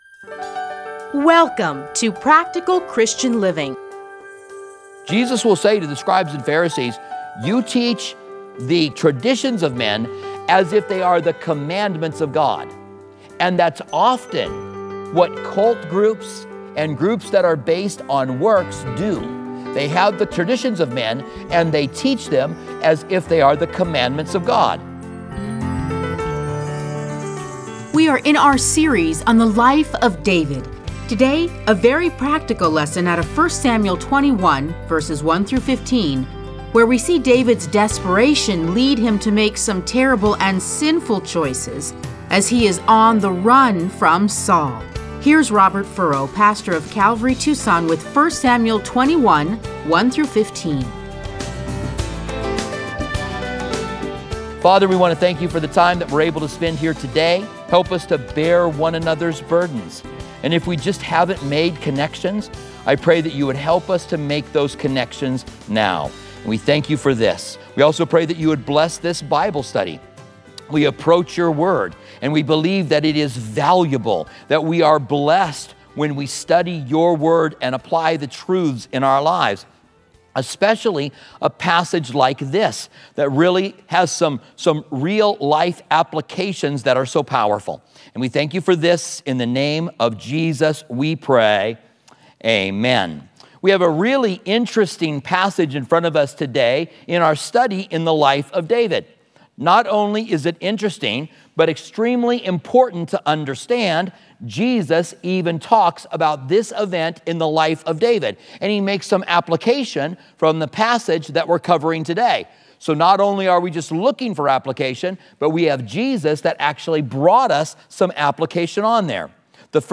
Listen to a teaching from 1 Samuel 21:1-15.